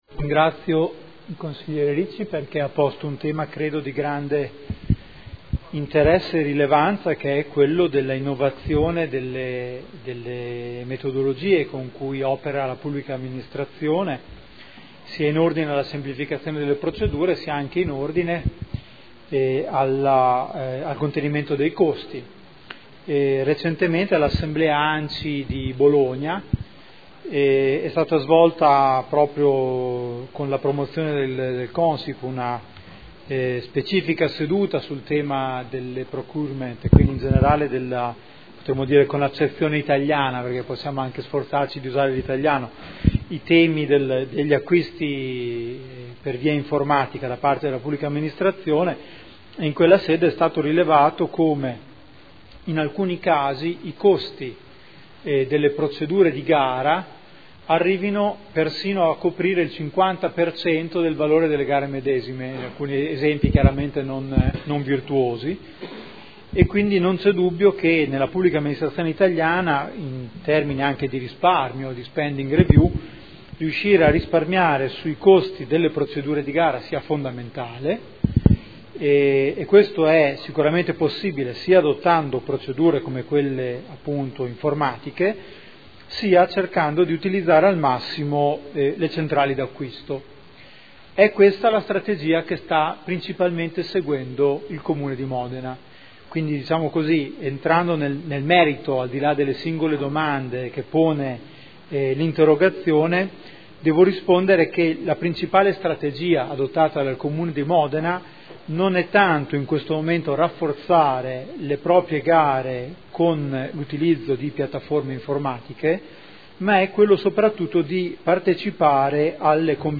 Seduta del 22/10/2012. Risponde a interrogazione del consigliere Ricci (Sinistra per Modena) avente per oggetto: “E-procurement”